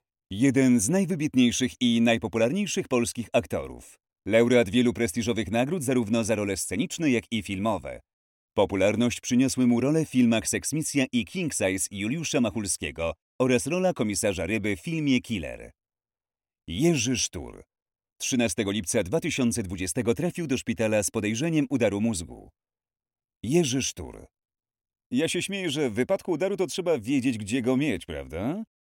Male 30-50 lat
Zapowiedź telefoniczna
Narracja